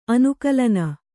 ♪ anukalana